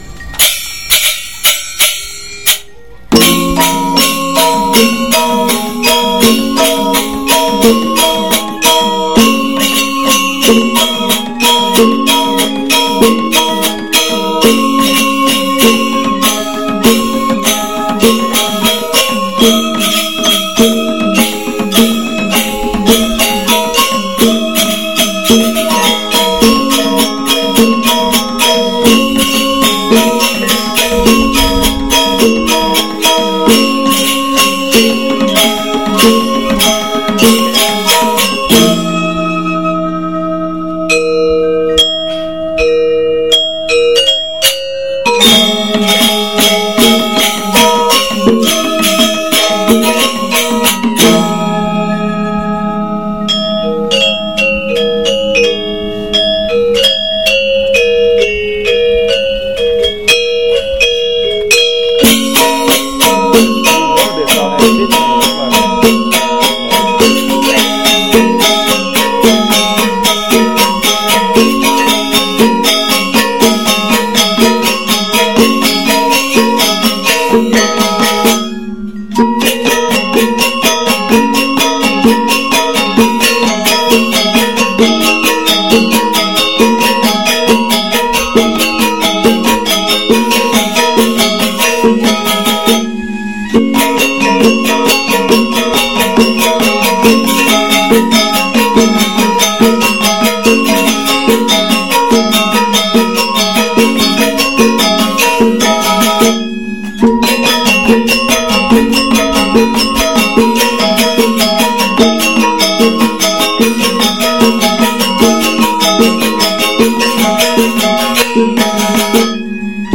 Balinese Gamelan Angklung Cremation Music